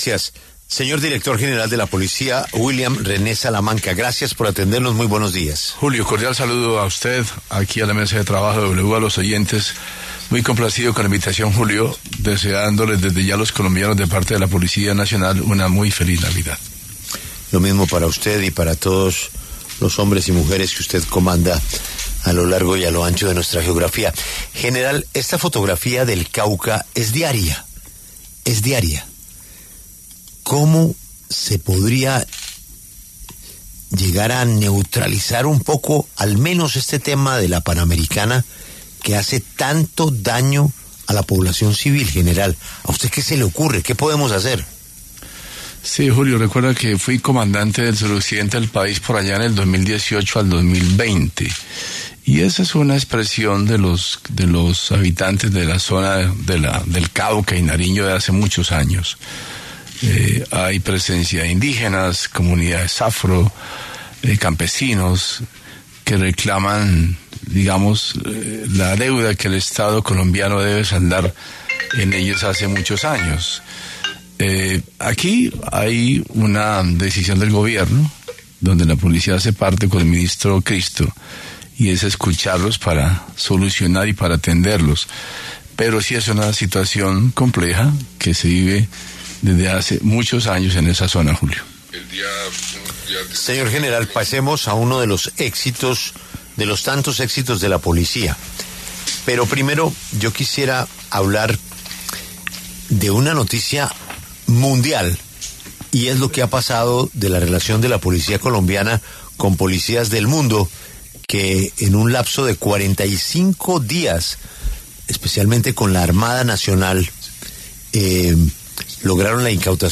El general William René Salamanca, director de la Policía Nacional, conversó con La W acerca de las estrategias contra el crimen organizado y otros temas relacionados con la seguridad del país.